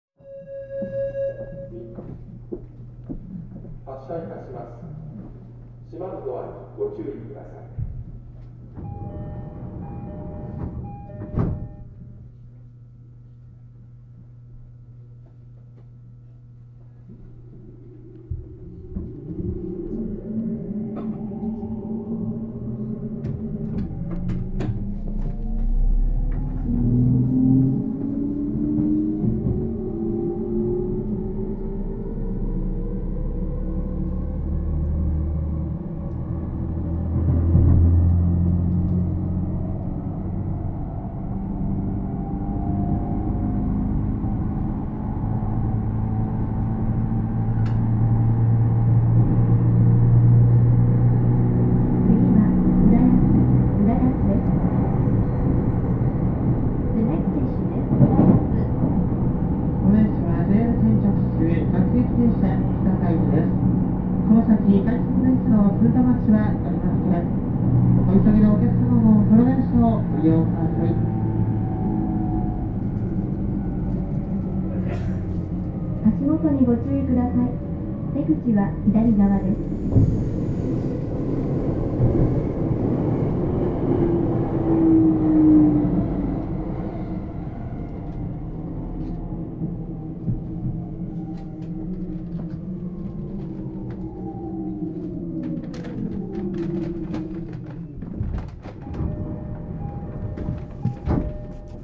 走行音
録音区間：南行徳～浦安(お持ち帰り)